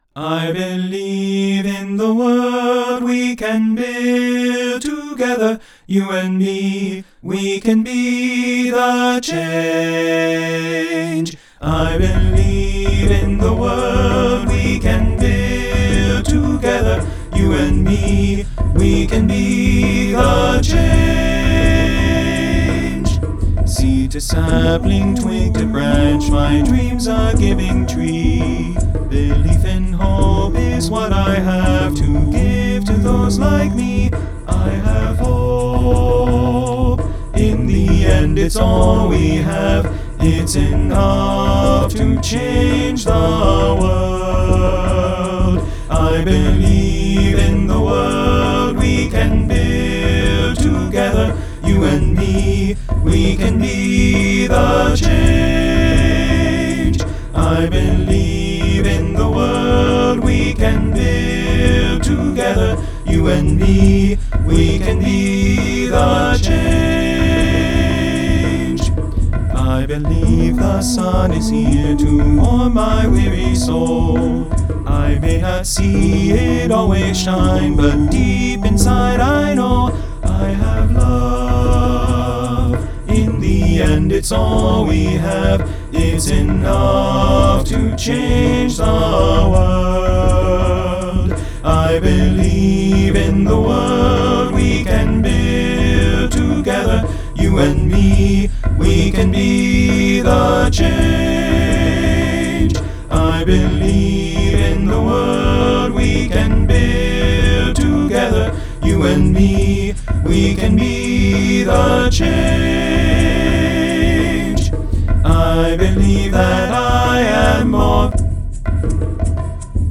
SATB and percussion